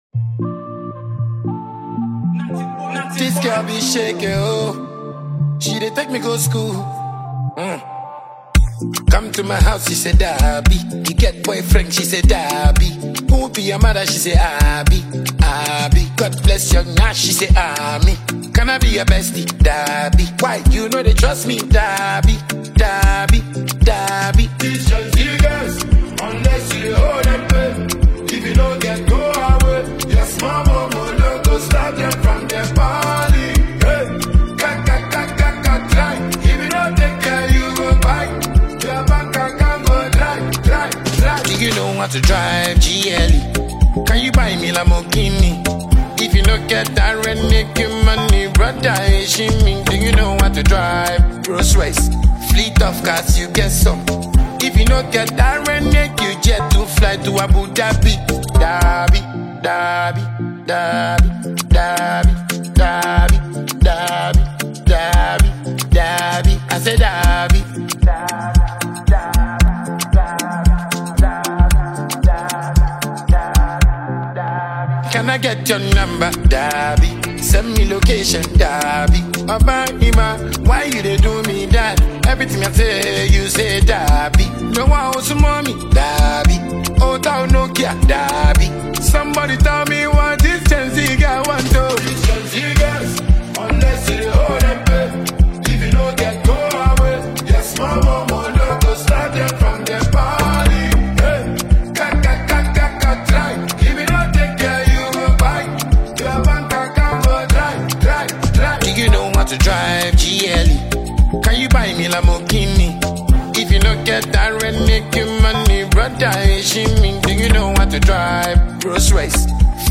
Ghanaian dancehall heavyweight